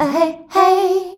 AHEHEY  D.wav